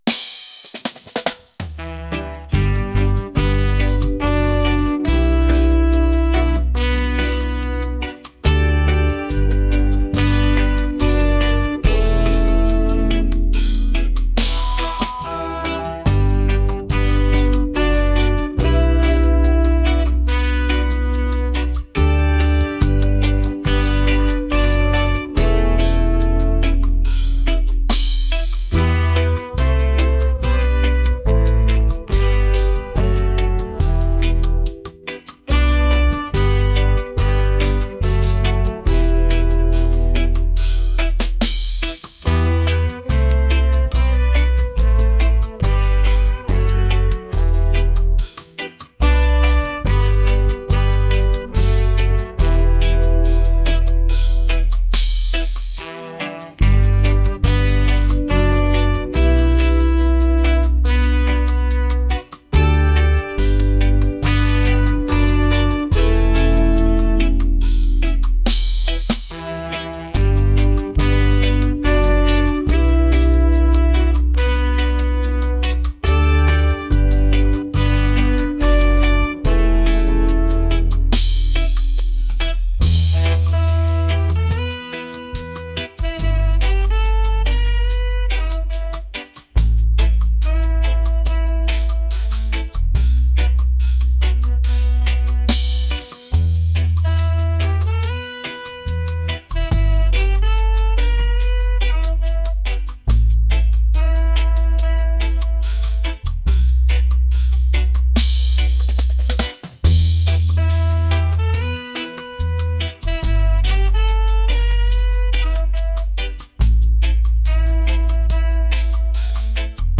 groovy | soulful | reggae